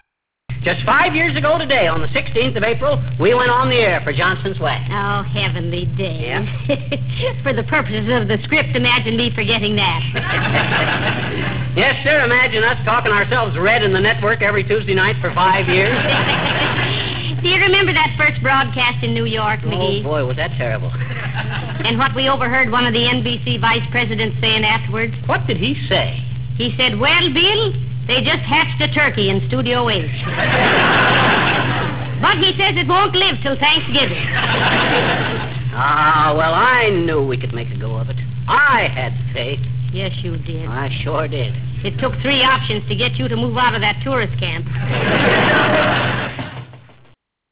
RealAudio 2.0 clip: Fibber and Molly talk about the early days on their fifth anniversary show. 1940.